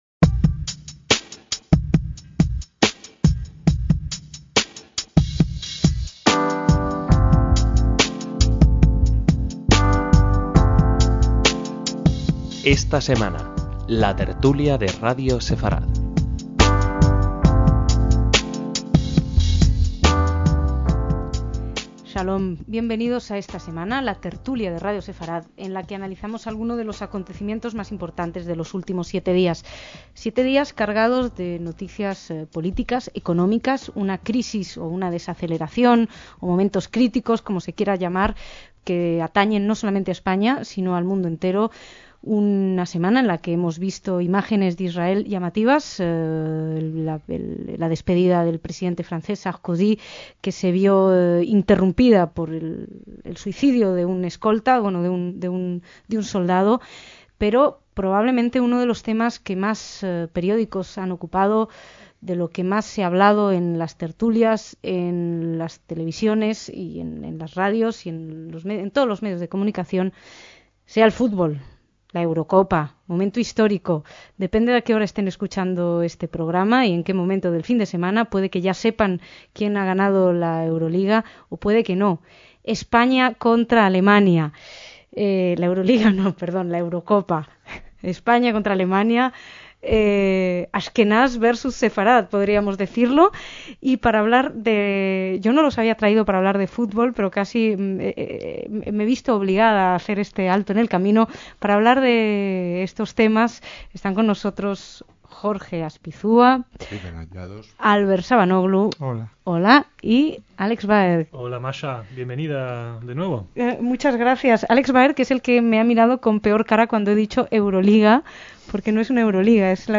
DECÍAMOS AYER (28/6/2008) - A pesar del título, lo más actual en los tiempos en que se grabó esta tertulia el fútbol internacional acaparaba la atención, aunque los temas abordados fueron varios.